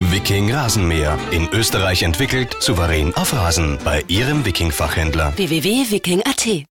Österreichischer Werbesprecher aus TV-Radio-Werbung mit eigener Aufnahmemöglichkeit, Sprecher für Werbespots, Telefonansagen und Imagefilme.
Sprachalter: 25-45 Jahre Sprachen: Deutsch (Österreich) Dialekte: österreichisch, wienerisch Stimmlage: mittelkräftig, frisch, dynamisch, seriös
Sprechprobe: eLearning (Muttersprache):